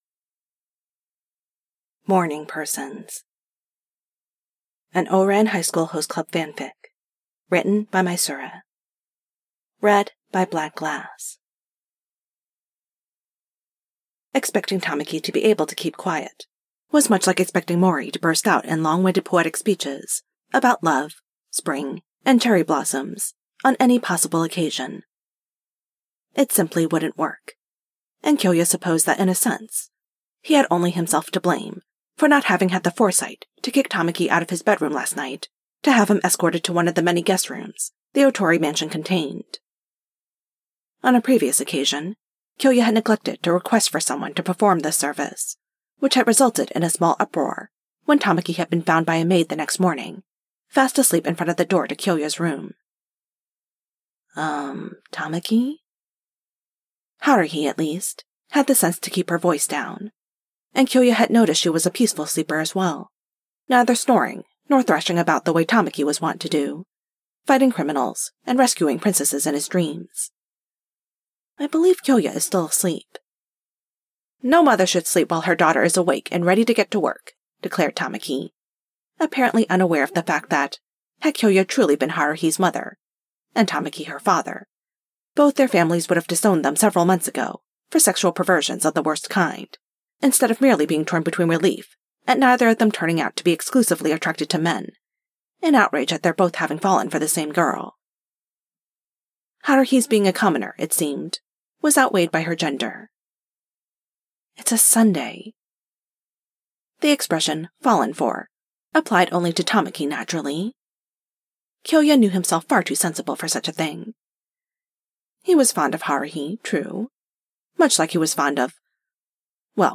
A podfic of Morning Persons by misura.